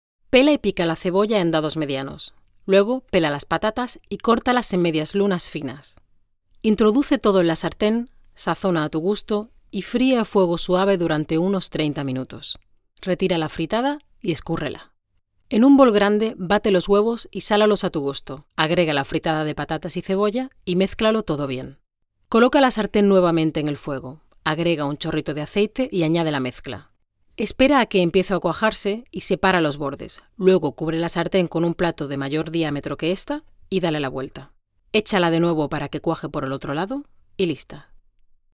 Spanisch. 2014 SPRACHEN Deutsch: Flieβend (C2) Englisch: Flieβend (C1) Italienisch: Gut (A2) Spanisch: Muttersprache (europäisch) Akzente: Castellano, Andalusisch, Mexikanisch, Argentinisch Stimmalter: 30 – 40 Stimme: voll, frisch, dynamisch, warm, facettenreich, weich.
Sprechprobe: eLearning (Muttersprache):